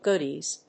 /ˈgʊdiz(米国英語), ˈgʊdi:z(英国英語)/